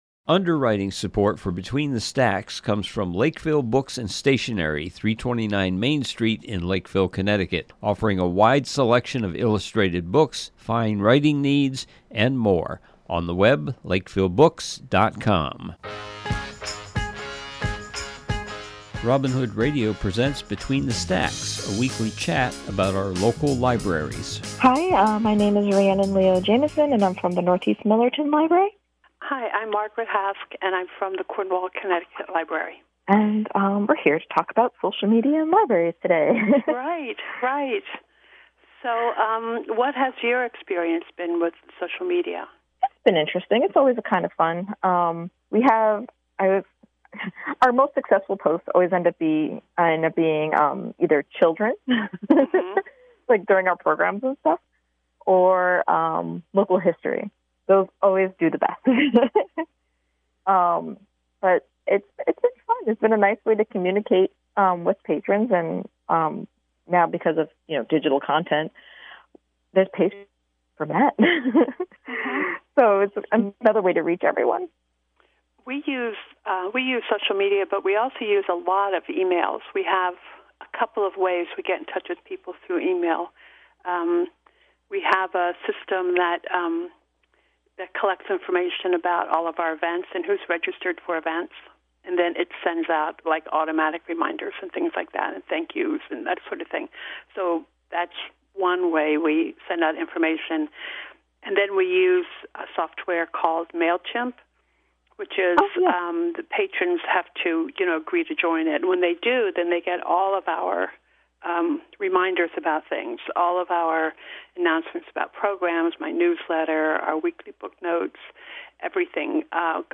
This program is a conversation